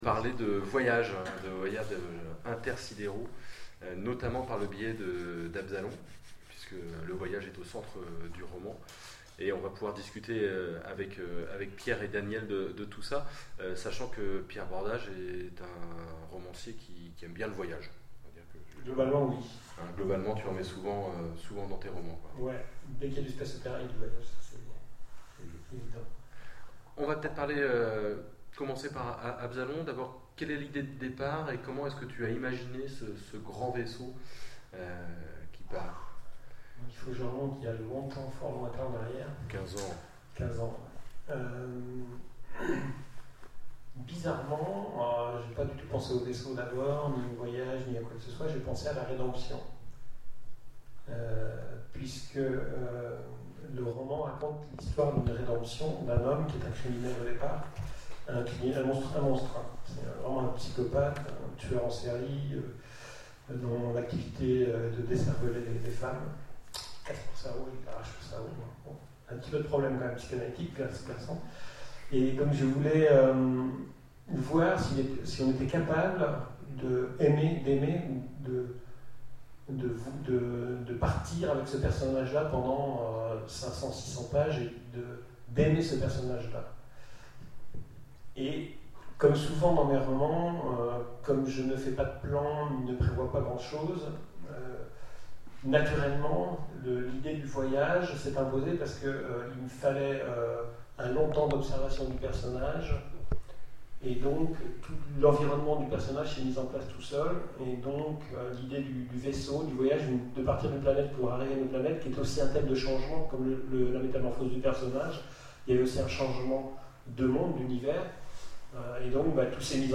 Mots-clés Rencontre avec un auteur Vulgarisation scientifique Conférence Partager cet article